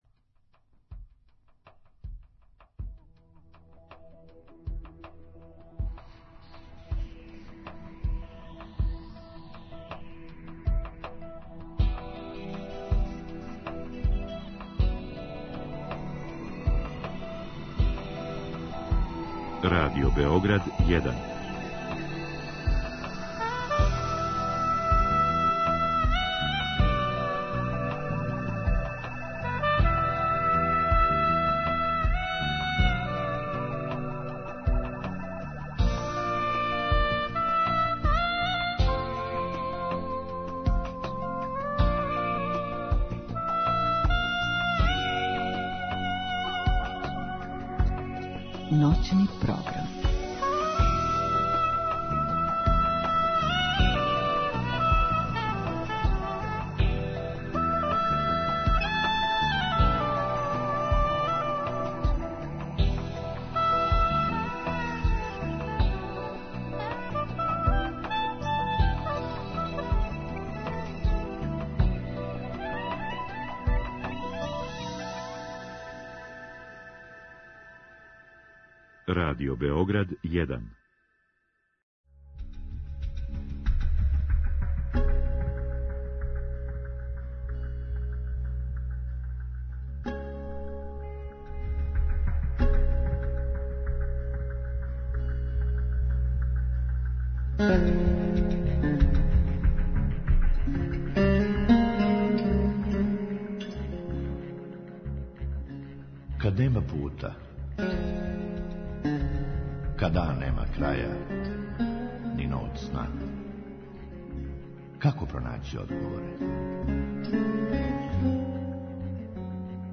Други сат је резервисан за слушаоце, који у програму могу поставити питање гошћи.